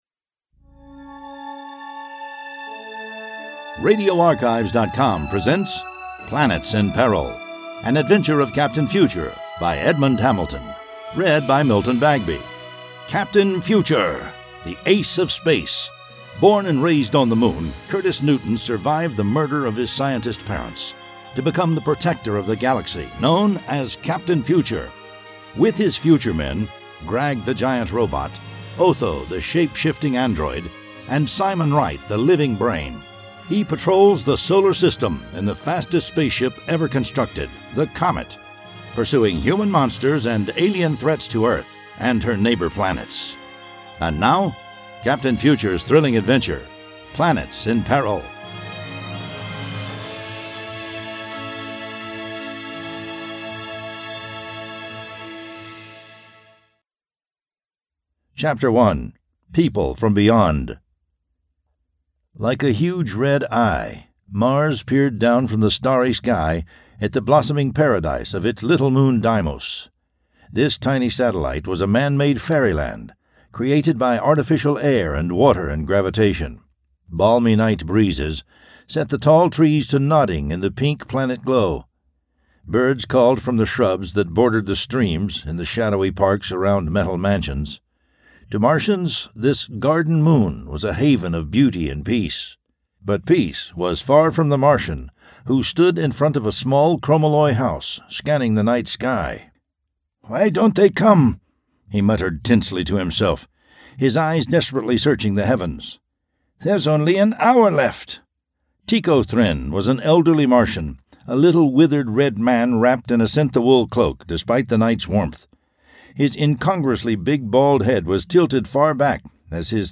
Captain Future Audiobook #12 Planets in Peril